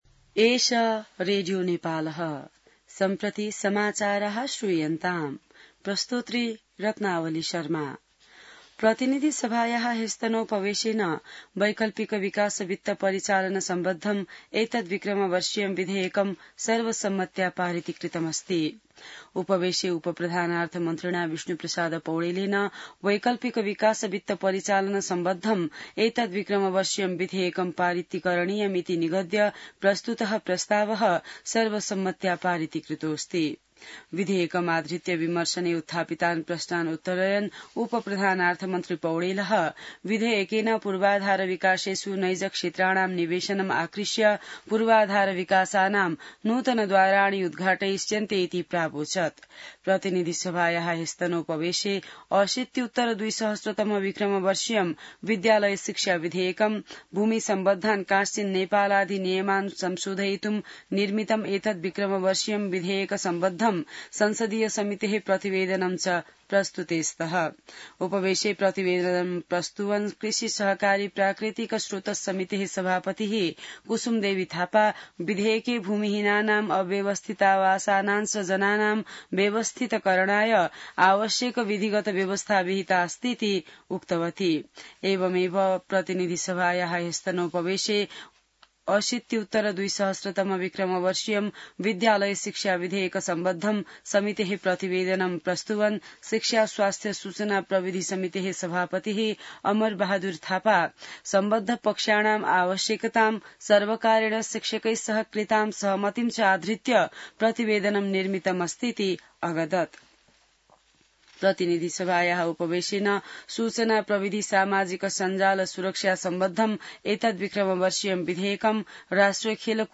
संस्कृत समाचार : ७ भदौ , २०८२